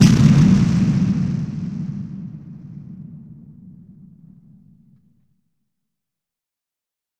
low mid afar explosion 1
battle bomb boom explosion fire-crackers firecrackers fire-works fireworks sound effect free sound royalty free Memes